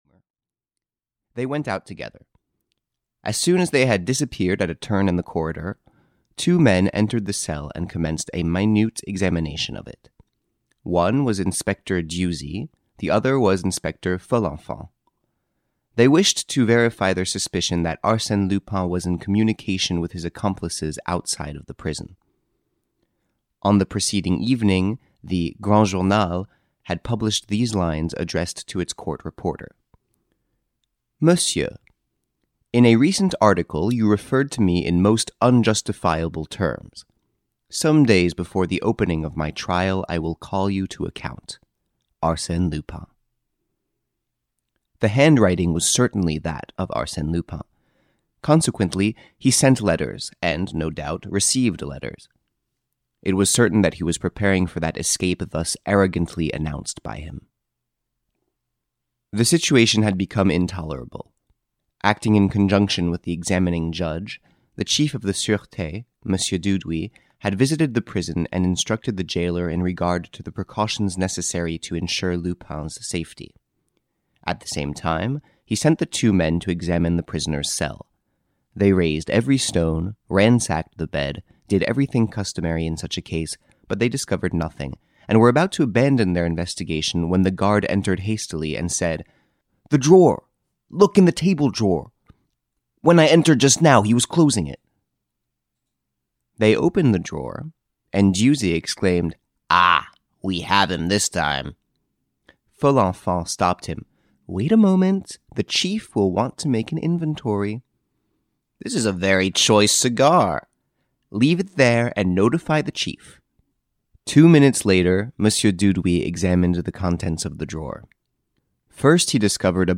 The Escape of Arsène Lupin, the Adventures of Arsène Lupin the Gentleman Burglar (EN) audiokniha
Ukázka z knihy